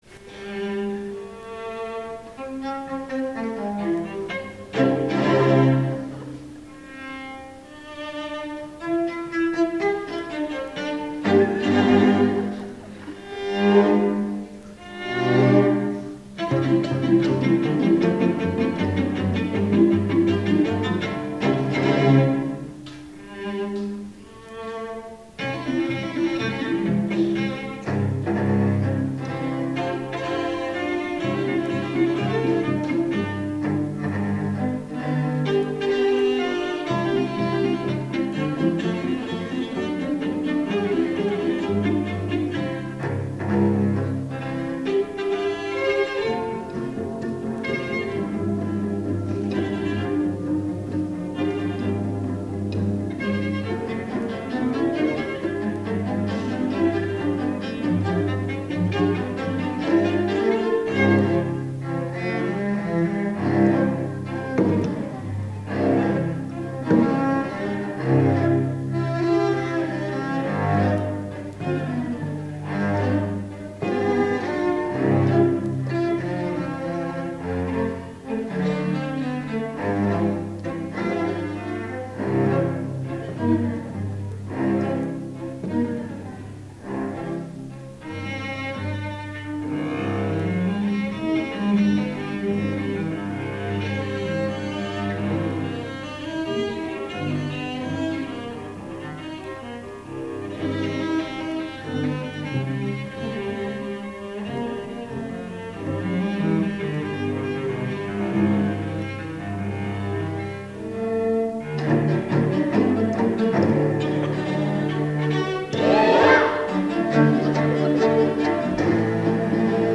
for Six Celli (1998)